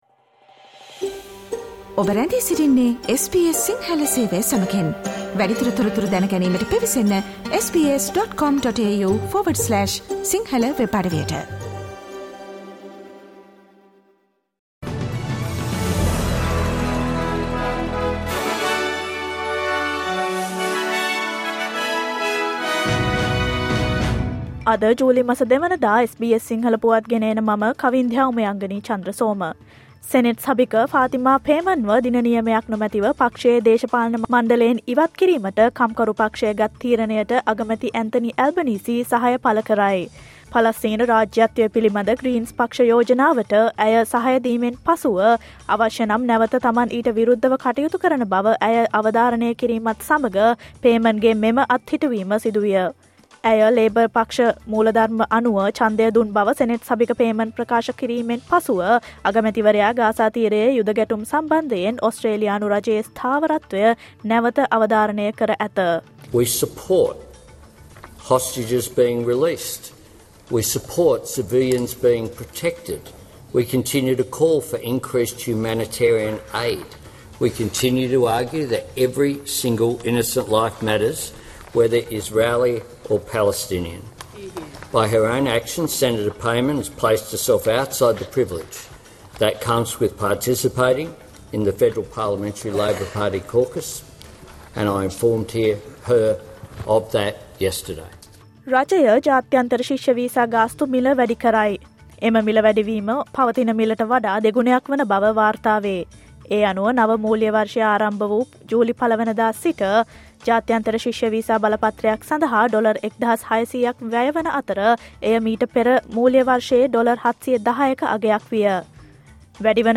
Australia's news in English, foreign and sports news in brief.